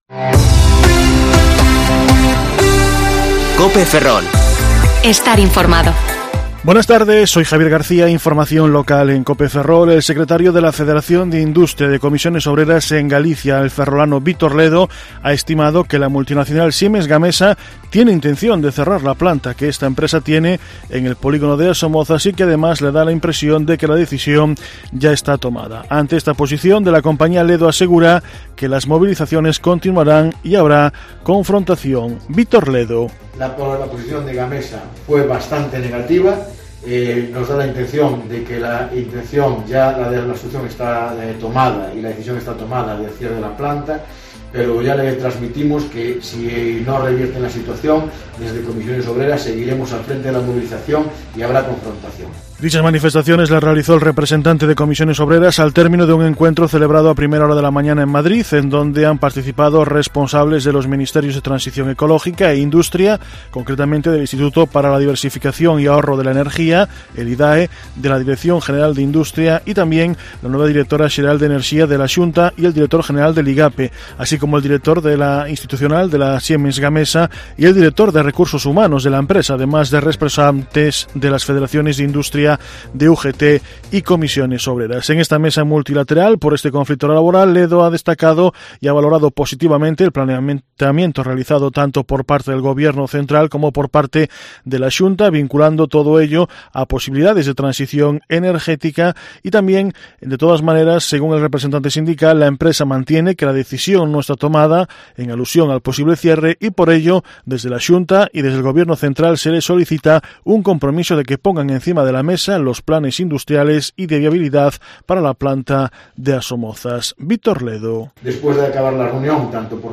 Informativo Medidía COPE Ferrol 15/12/2020 (De 14,20 a 14,30 horas)